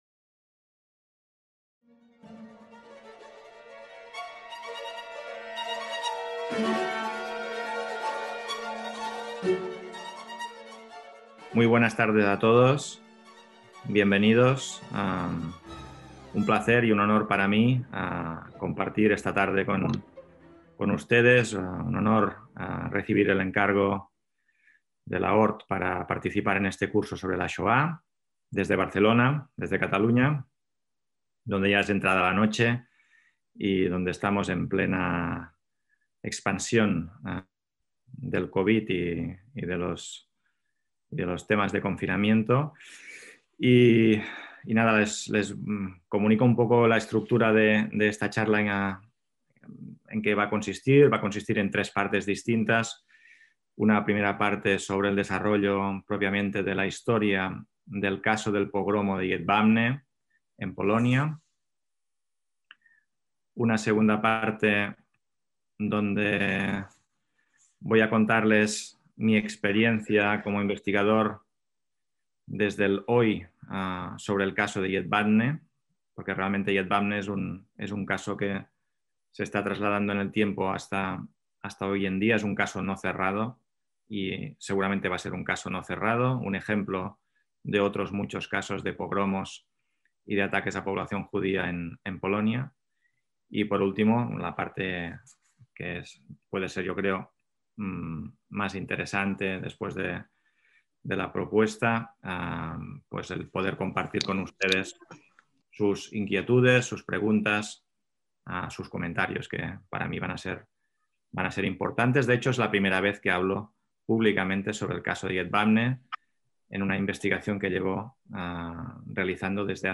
ACTOS "EN DIRECTO" - Se conoce como Masacre de Jedwabne al asesinato de centenares de judíos (1600 según algunas fuentes, 340 según el informe oficial polaco del Instituto de la Memoria Nacional, a manos de sus vecinos polacos católicos en el pueblo homónimo el 10 de julio de 1941.